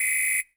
BWHISTLELG.wav